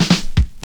32DR.BREAK.wav